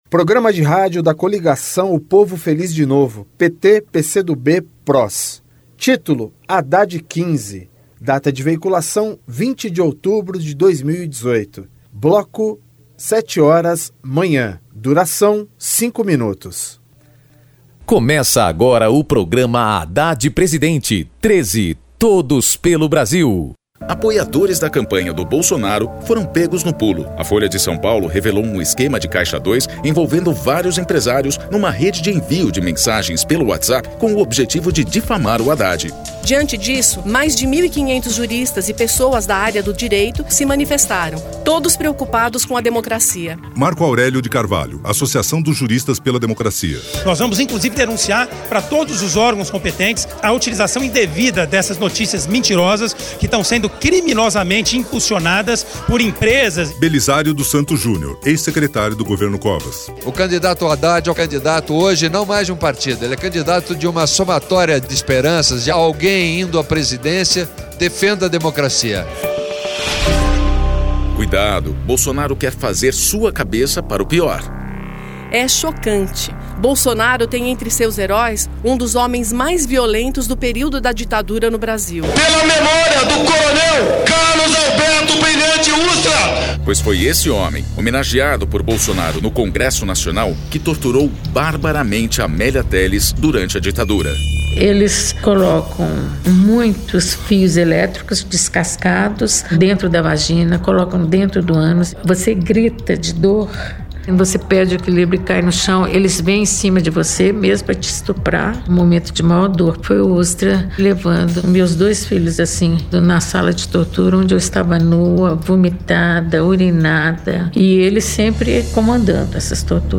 TítuloPrograma de rádio da campanha de 2018 (edição 45)
Gênero documentaldocumento sonoro